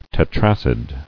[te·trac·id]